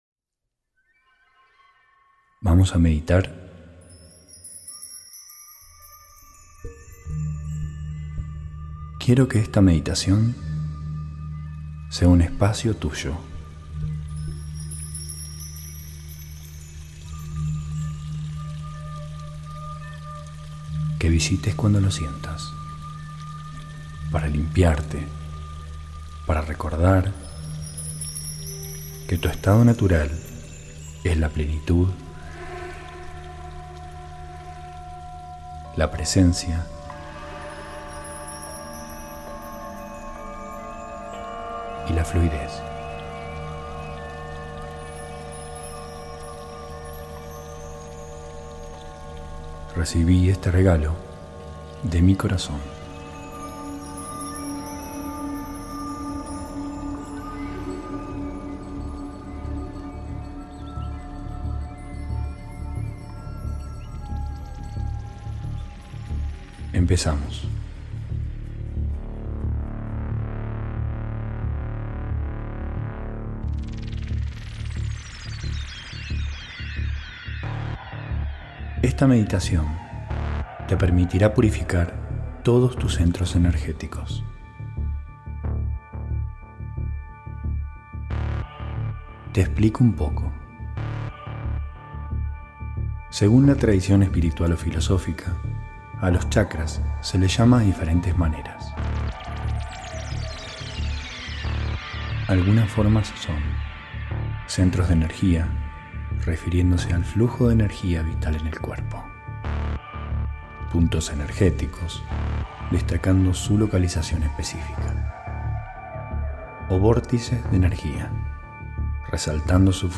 Recomiendo usar auriculares.